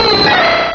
-Replaced the Gen. 1 to 3 cries with BW2 rips.
tyrogue.aif